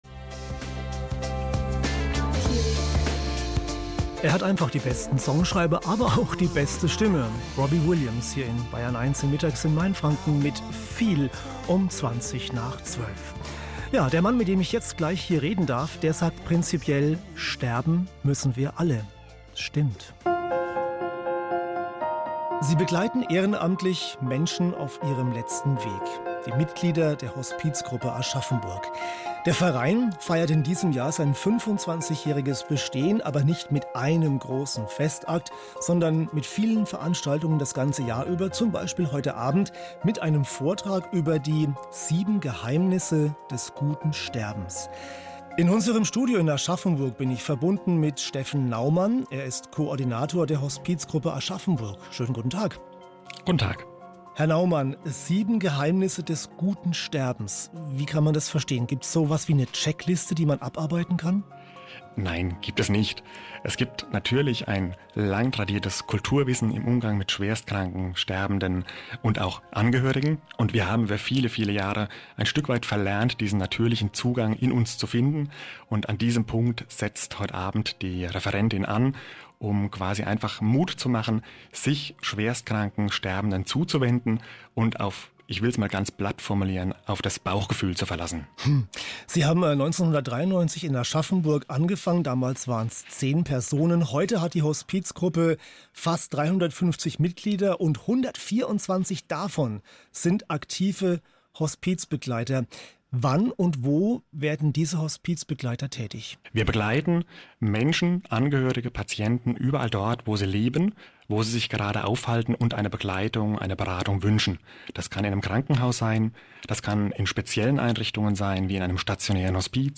Hier ein Radiomitschnitt von Bayern 1 im Vorfeld der Veranstaltung.